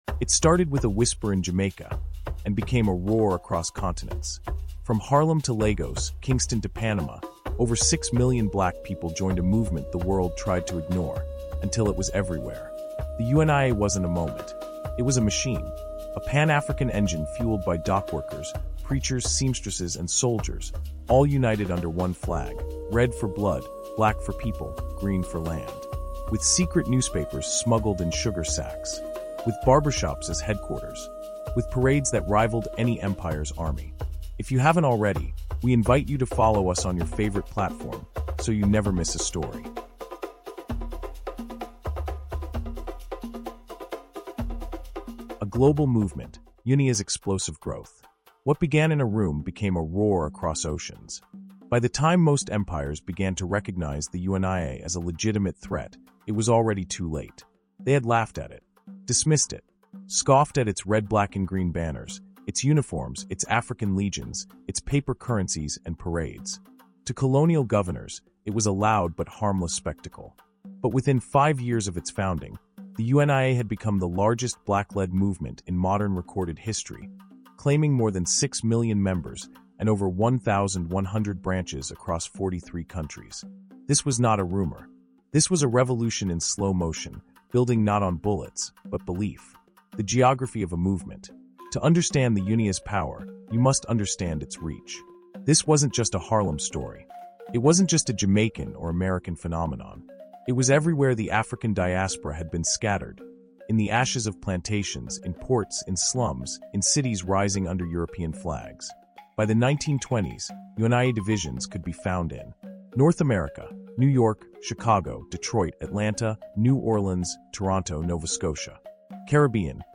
UNIA: UNIA’s Explosive Growth | Audiobook